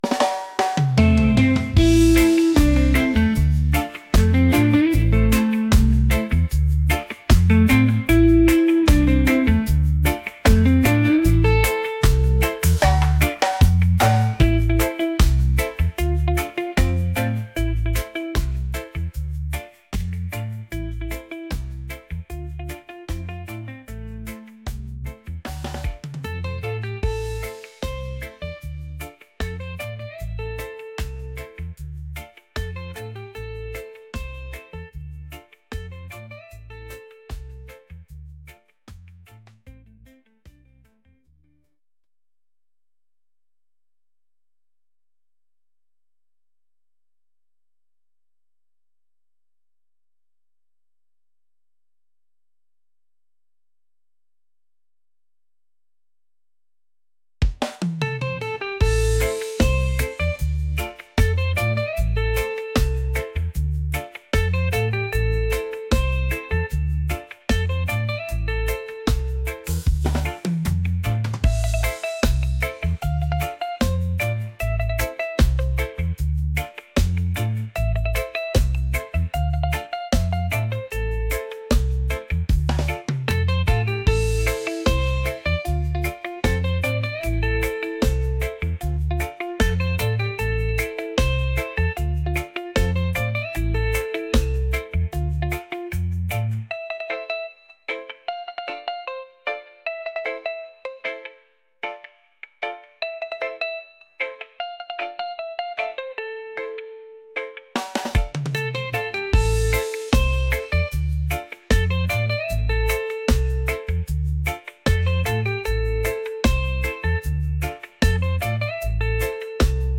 upbeat | reggae | groovy